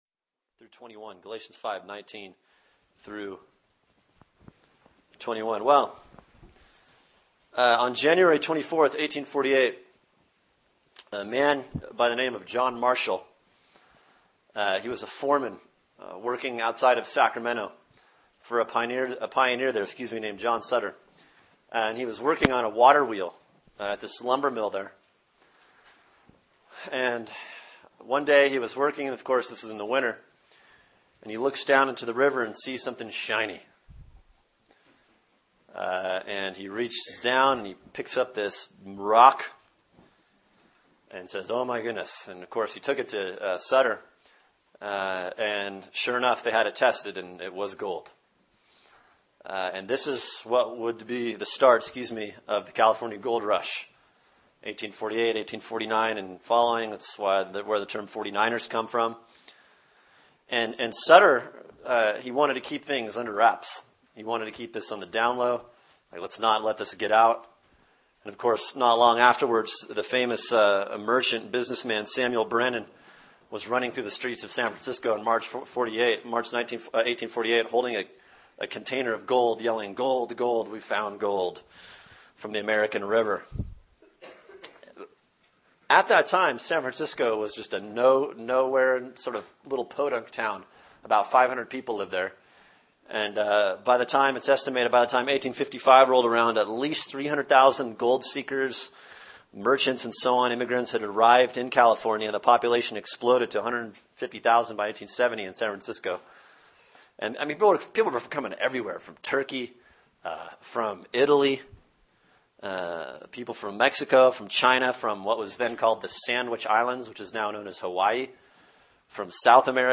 Sermon: Galatians 5:19-21 “A Soul Check-Up” | Cornerstone Church - Jackson Hole